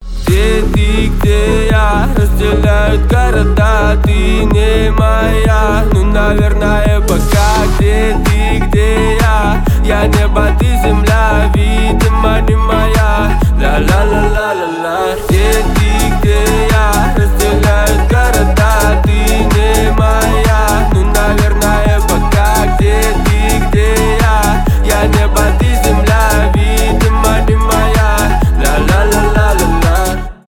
танцевальные
хип-хоп